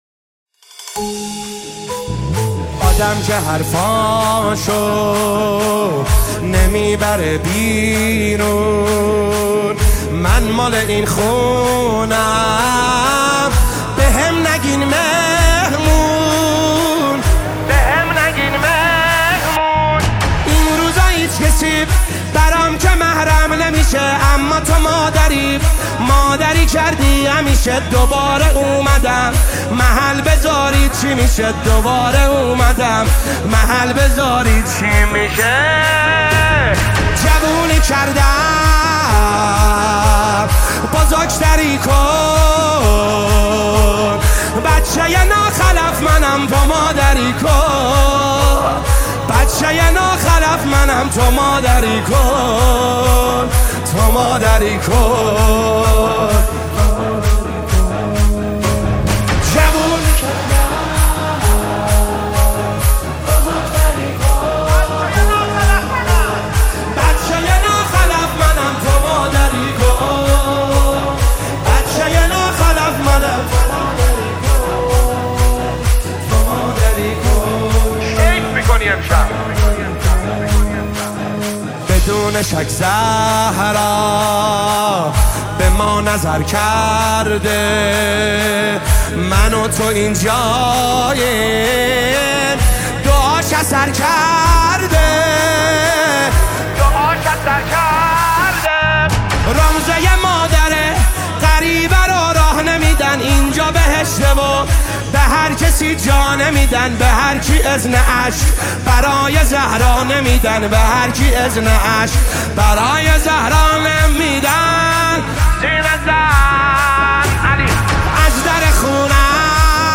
نماهنگ دلنشین
نماهنگ مذهبی مداحی مذهبی